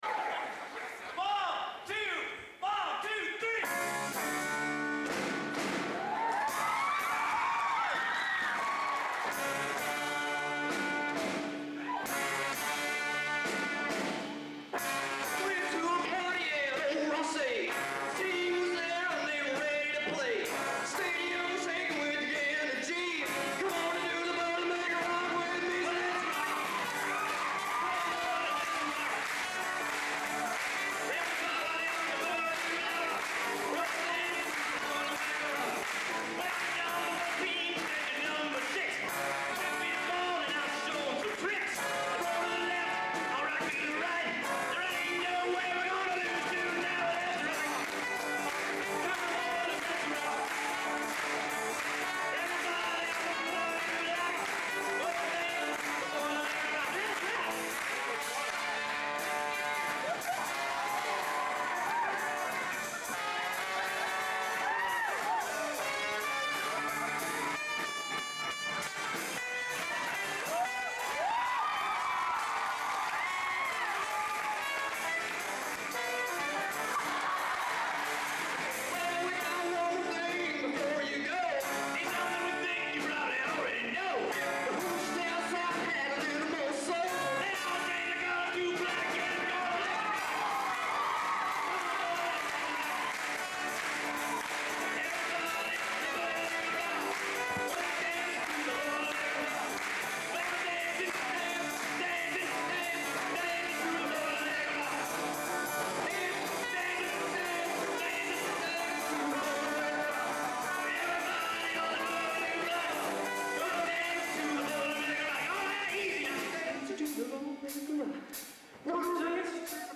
Location: Purdue Memorial Union, West Lafayette, Indiana
Genre: Humor/Parody Popular / Standards | Type: Specialty